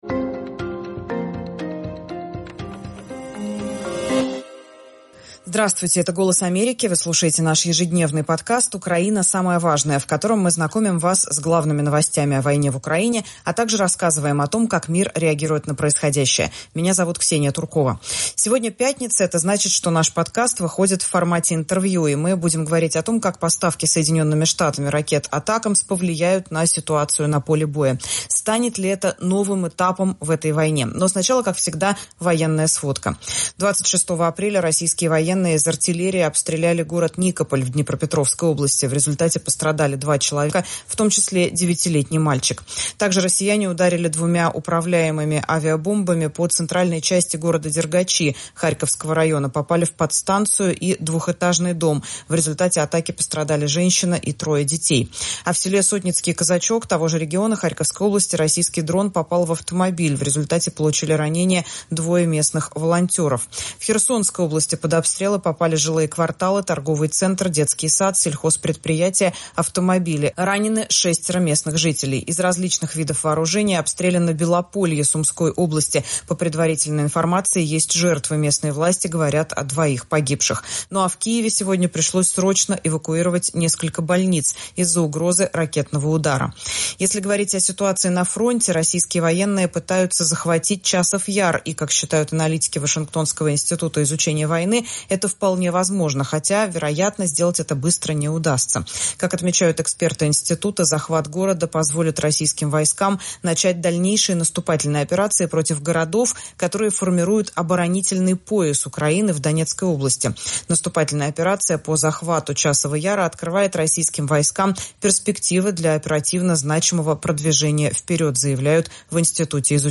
Дальнобойные ракеты и возможный перелом в войне (интервью)